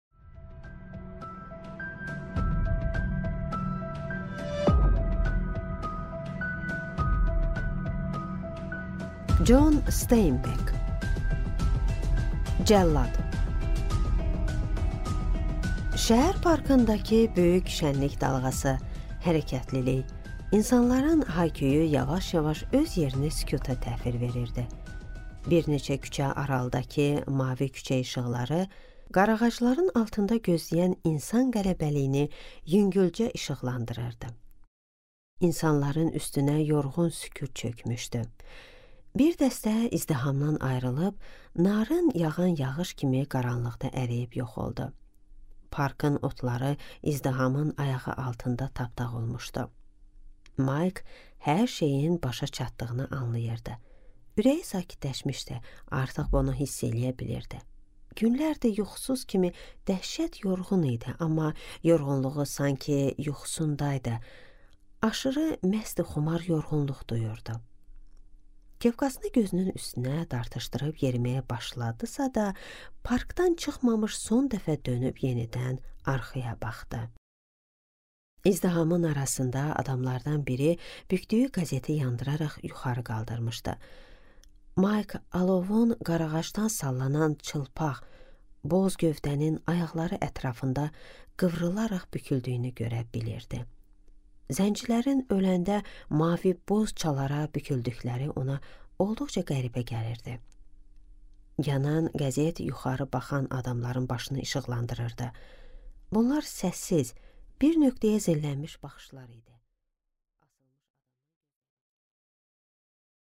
Аудиокнига Cəllad | Библиотека аудиокниг